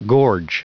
Prononciation du mot gorge en anglais (fichier audio)
Prononciation du mot : gorge